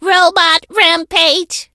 meg_ulti_vo_05.ogg